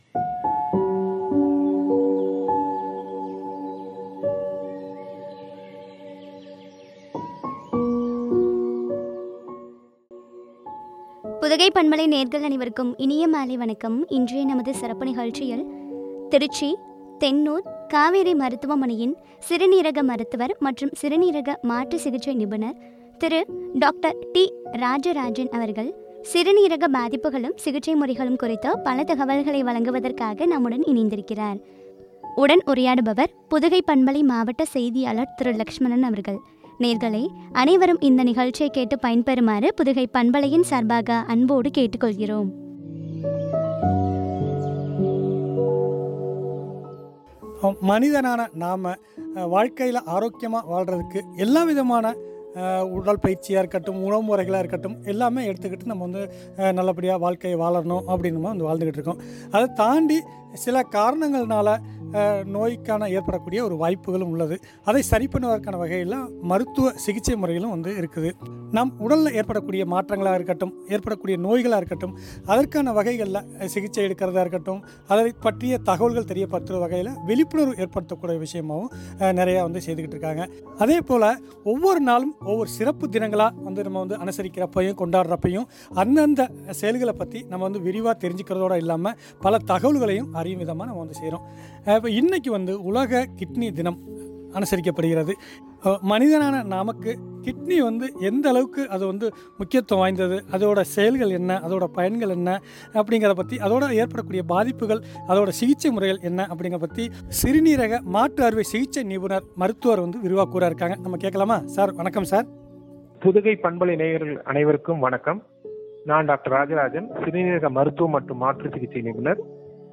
சிறுநீரக பாதிப்புகளும், சிகிச்சை முறைகளும் பற்றிய உரையாடல்.